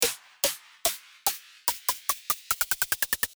スネアの音を徐々にドロップに向けてピッチを上げていく使い方です！
ピッチが上がりましたね！
snare-build-up2.mp3